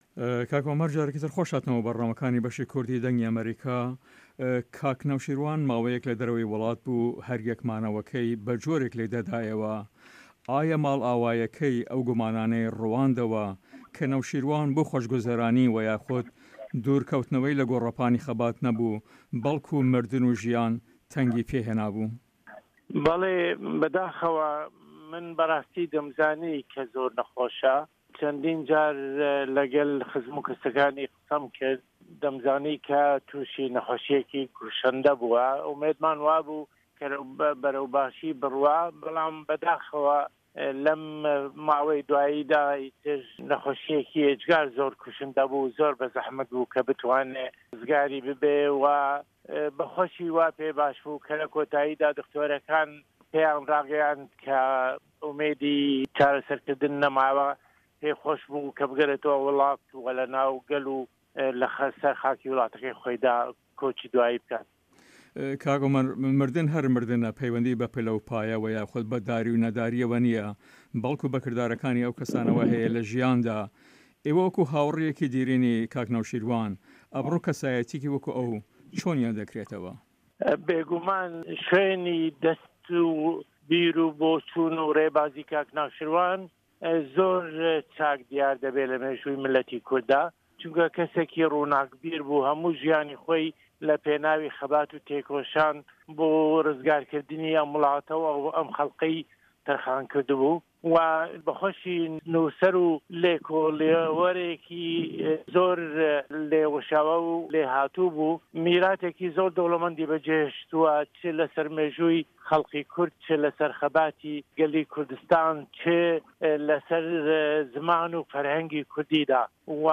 هه‌رێمه‌ کوردیـیه‌کان - گفتوگۆکان
Interview